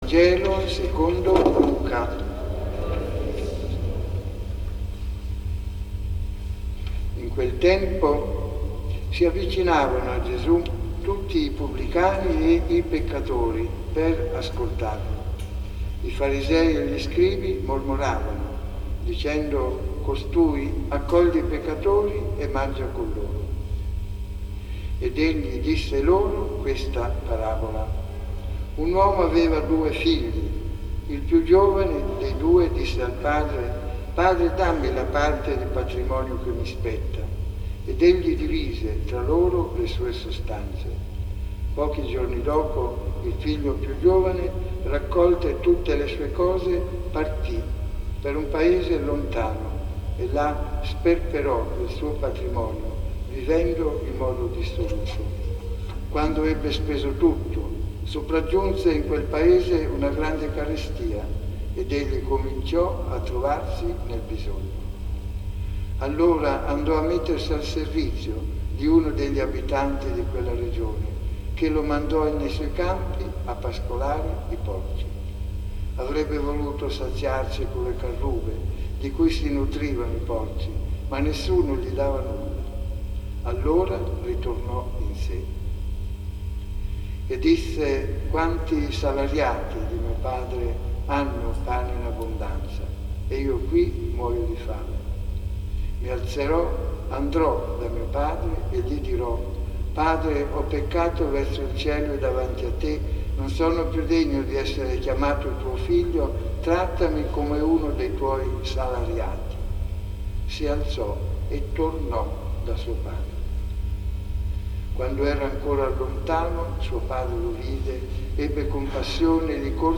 Omelia della notte sulle Letture della Creazione, del Sacrificio di Abramo e sulla morte degli Egiziani nel Mar Rosso.